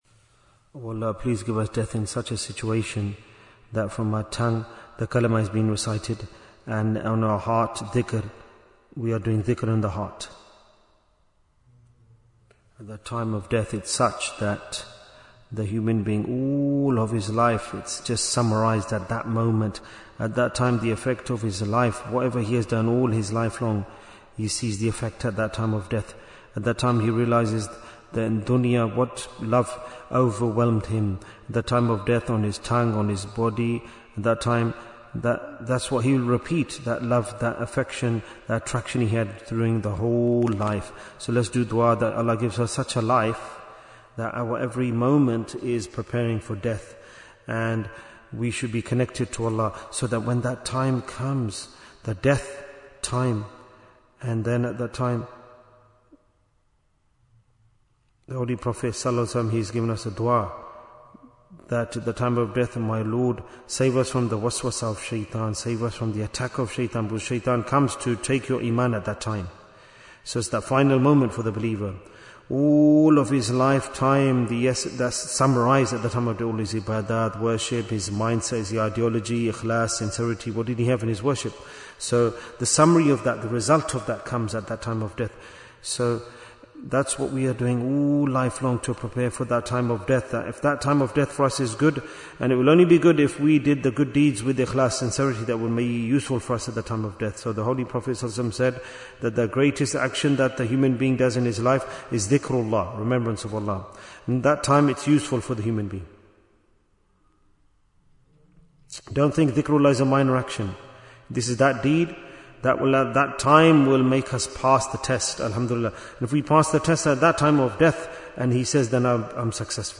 Why is Tazkiyyah Important? - Part 27 Bayan, 33 minutes7th April, 2026